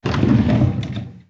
Abrir el cajón de un aparador
Sonidos: Acciones humanas
Sonidos: Hogar